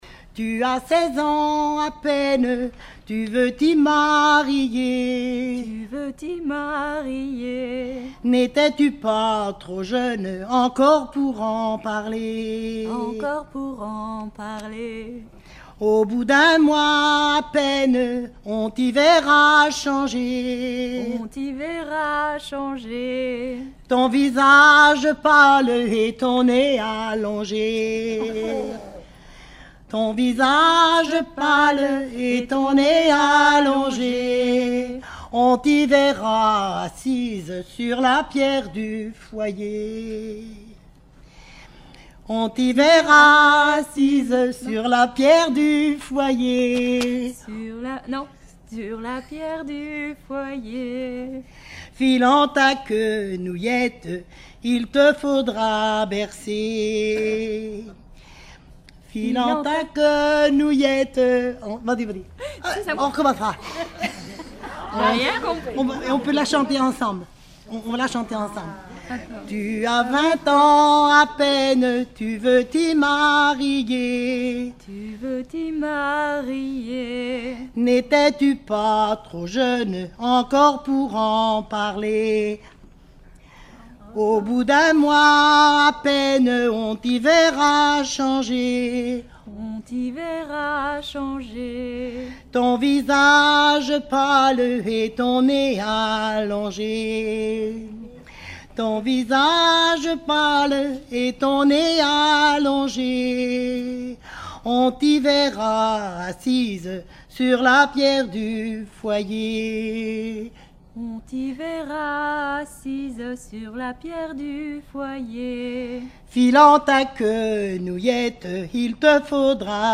Genre dialogue
Regroupement de chanteurs du canton
Pièce musicale inédite